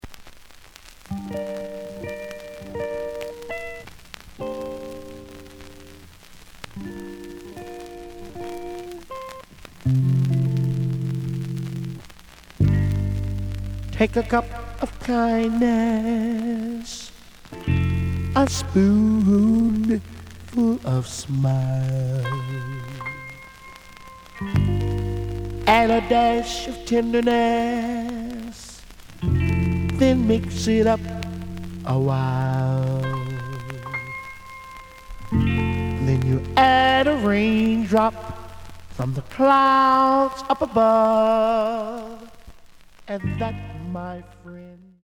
The audio sample is recorded from the actual item.
●Genre: Soul, 60's Soul
Looks good, but slight noise on both sides.